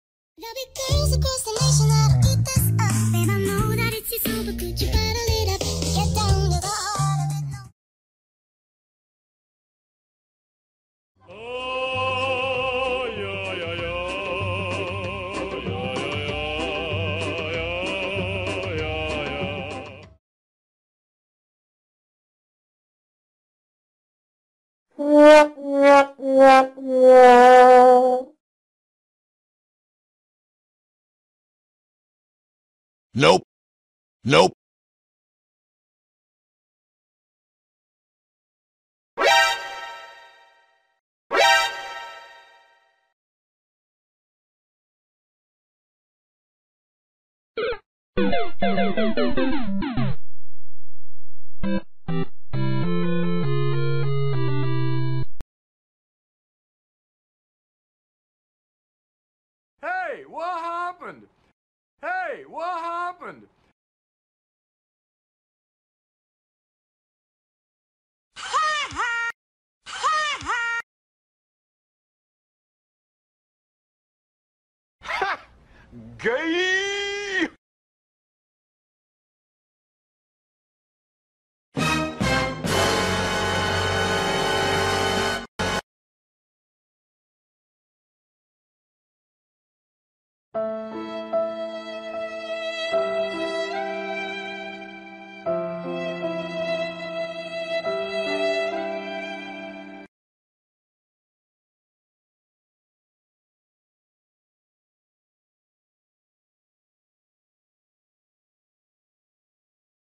SONIDOS-MOMENTOS-FALLIDOS.mp3